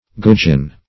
\Good"geon\